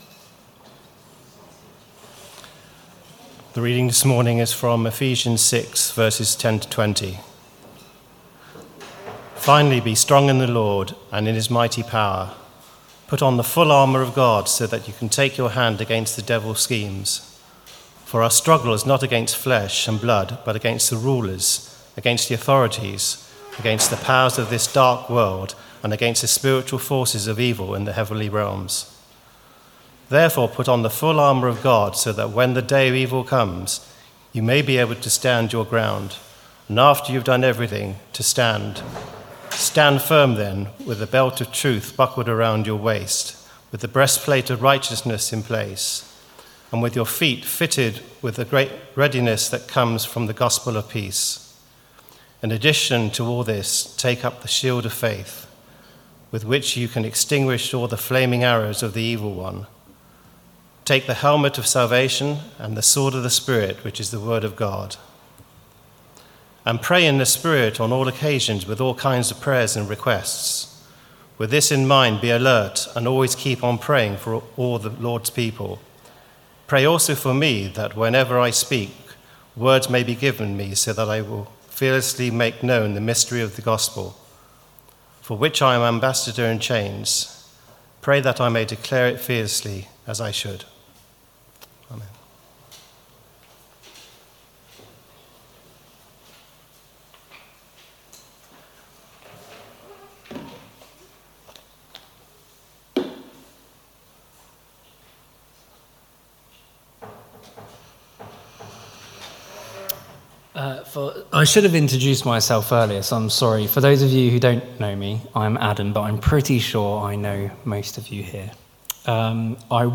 Sermon 10th Sept 2023 11am gathering
Family Gathering for Worship 11am 10th Sept 2023